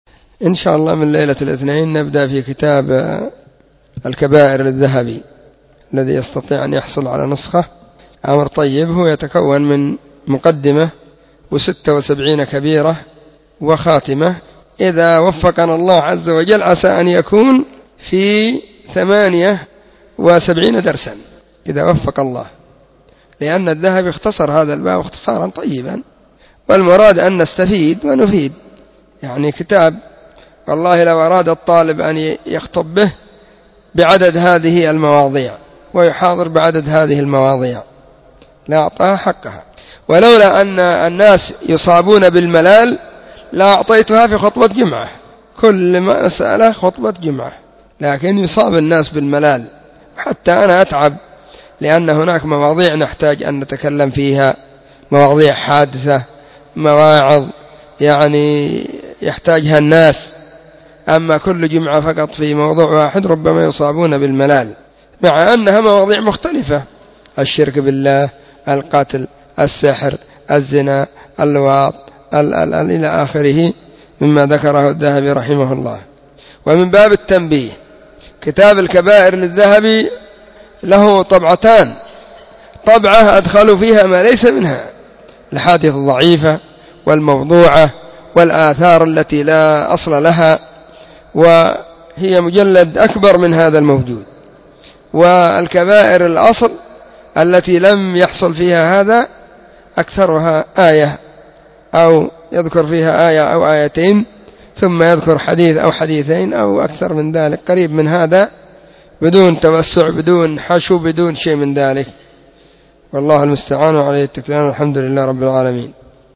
إعلان_عن_تدريس_كتاب_الكبائر_للذهبي.mp3